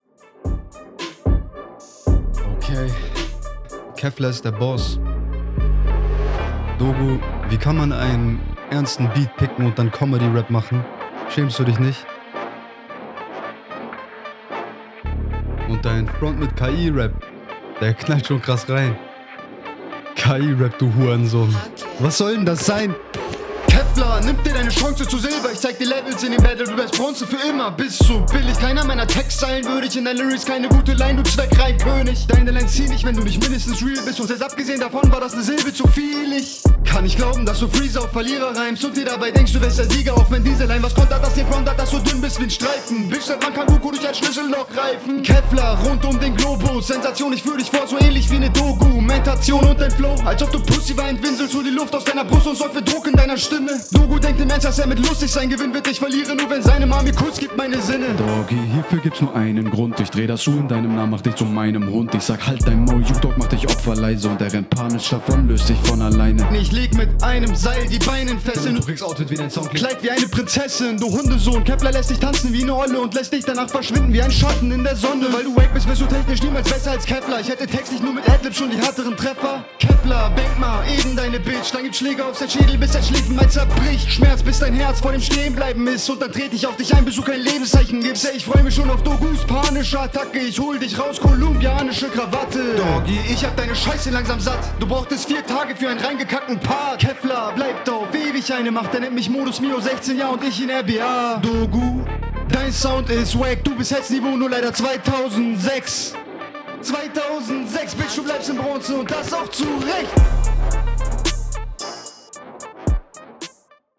Richtig guter Stimmeinsatz, geht nach vorne und ballert.
Bruder, das ist schon teilweise sehr offbeat.
Ein paar nette Ansätze, aber viele flowliche Unsicherheiten.